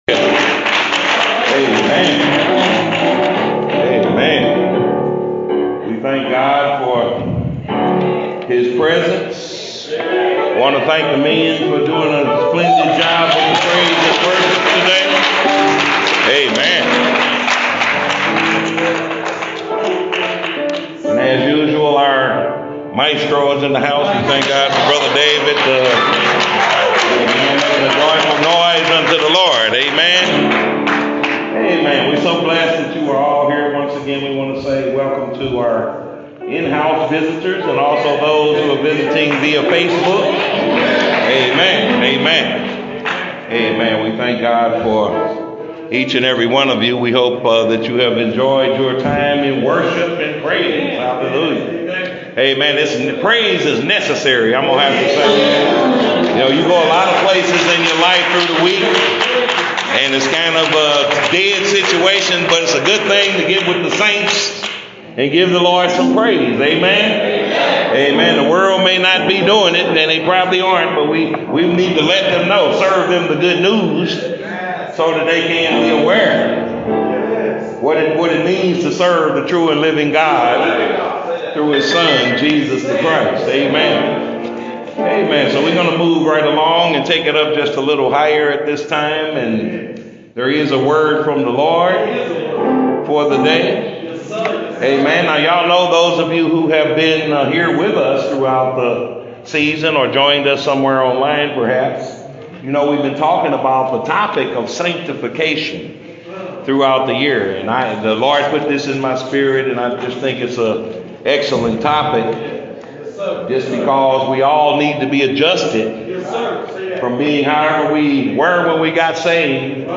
Old Testament Scripture Reading: Psalms 119:1 – 8 (KJV)